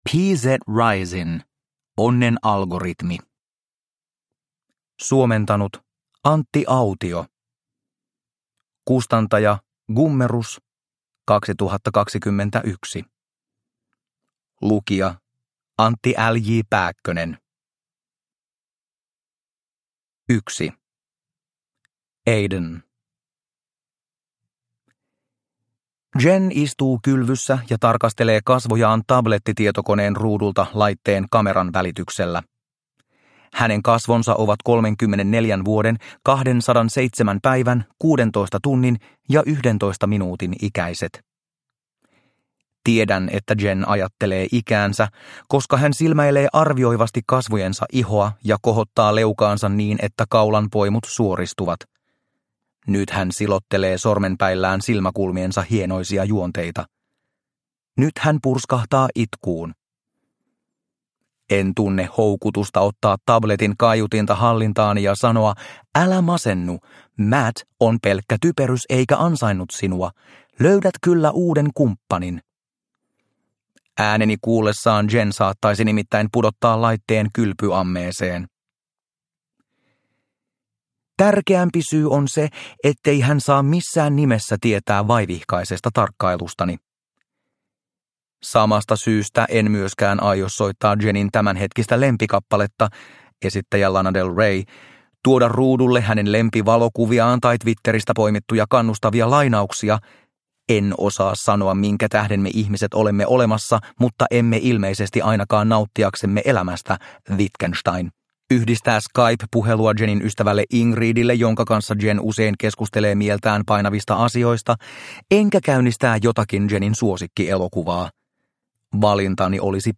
Onnen algoritmi – Ljudbok – Laddas ner